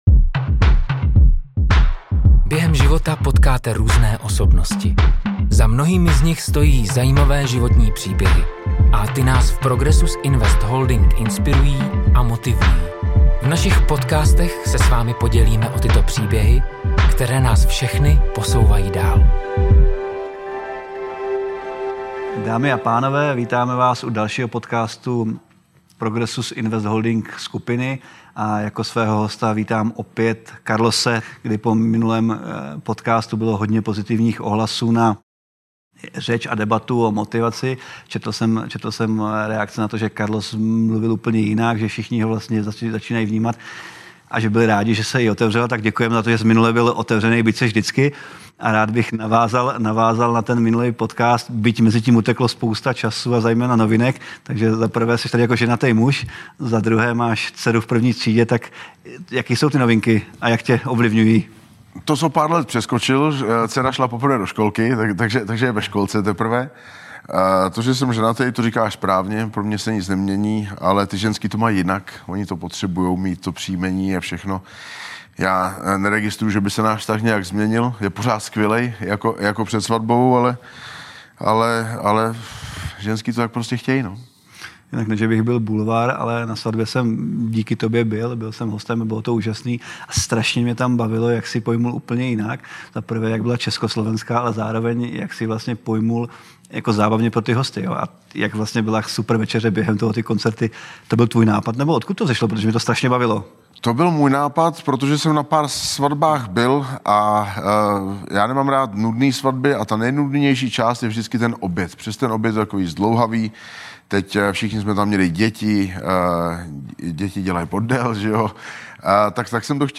Další díl podcastu přináší rozhovor s profesionálním zápasníkem, panem Karlosem Vémolou